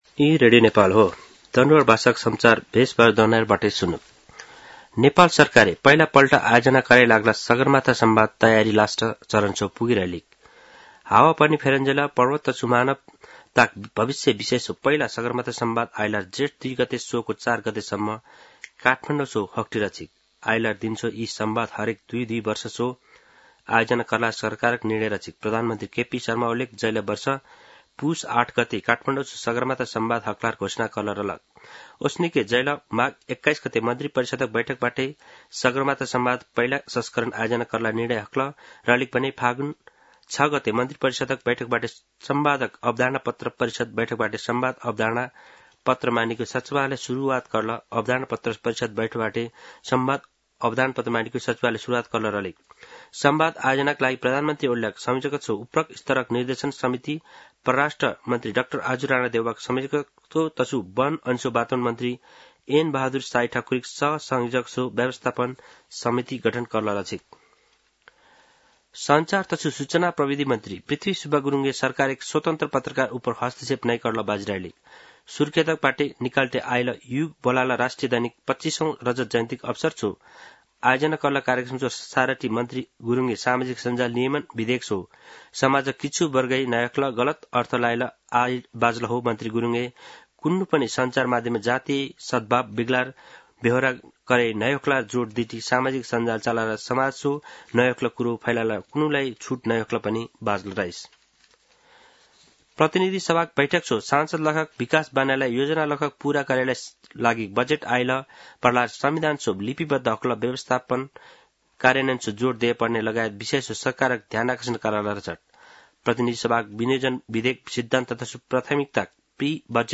दनुवार भाषामा समाचार : ३० वैशाख , २०८२
danuwar-news-.mp3